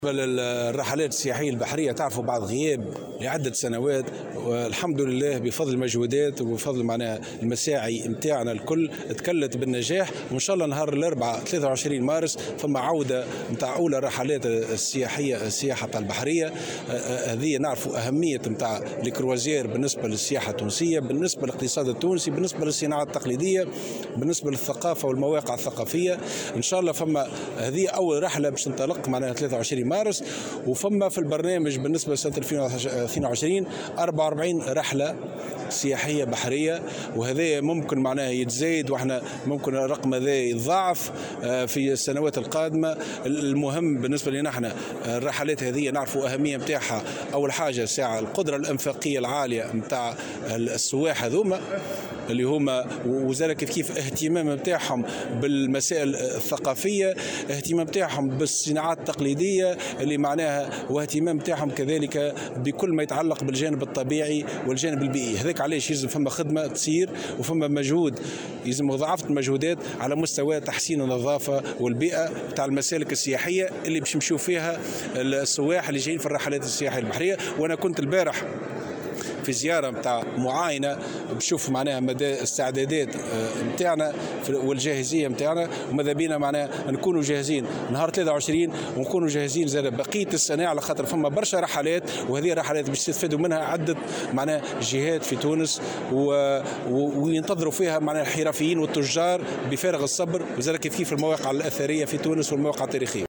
وأضاف في تصريح لمراسل "الجوهرة أف أم" على هامش صالون الابتكار في الصناعات التقليدية والخطط التسويقية للحرفيين، أنه من المنتظر أن تستقبل تونس خلال هذه السنة 44 رحلة سياحية بحرية على أن تكون أولى هذه الرحلات يوم 23 مارس الحالي.